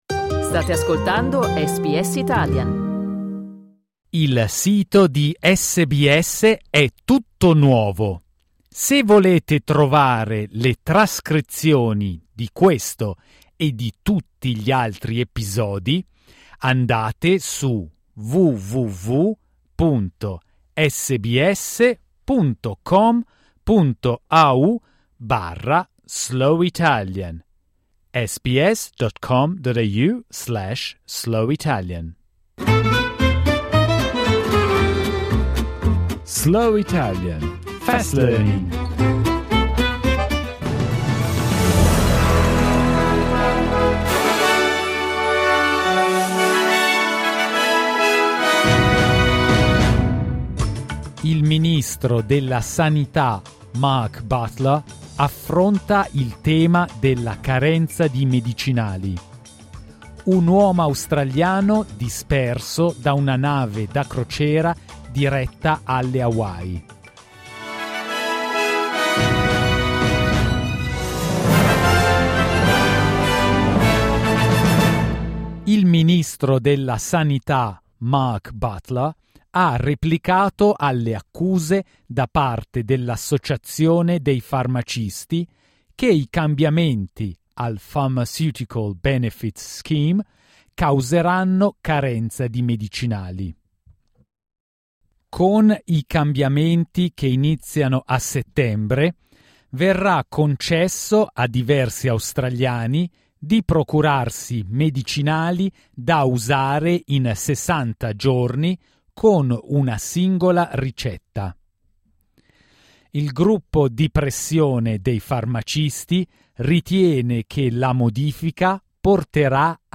SBS Italian's News bulletin, read slowly.